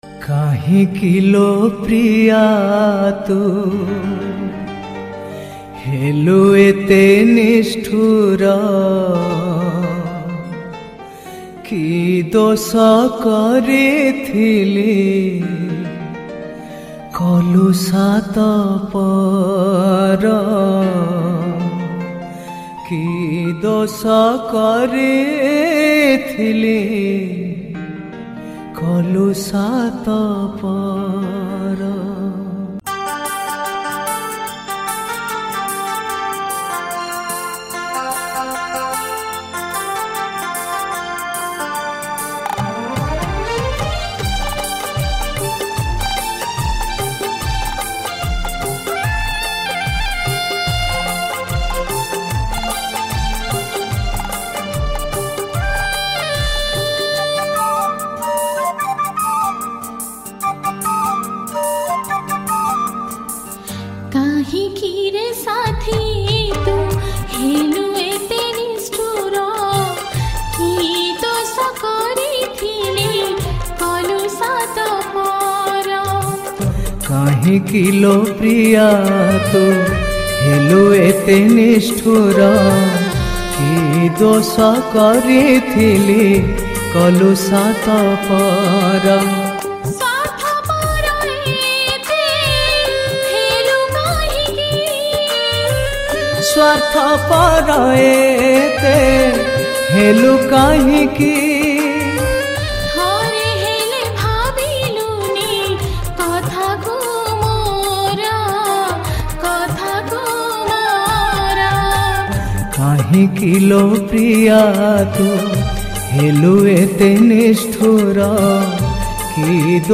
Sad Song
New Odia Album Songs